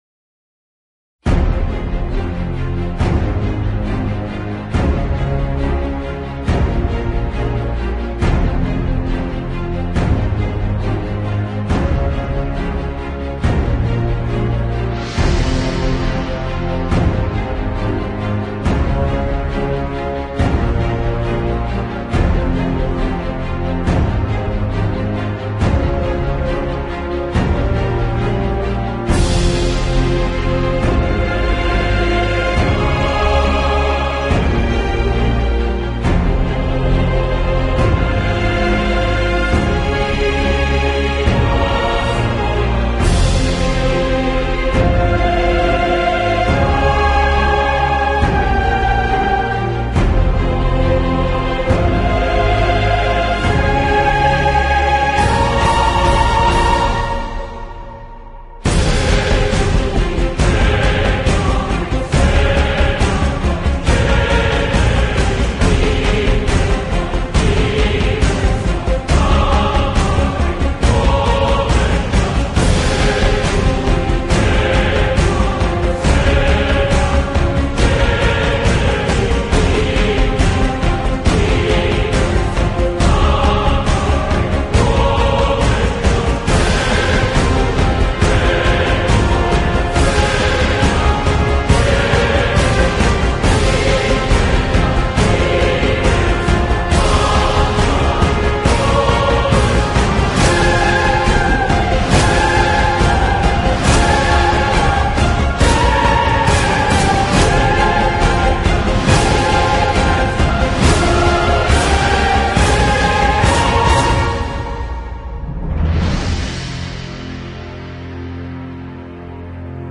МУЗЫКИ С ХОРОМ.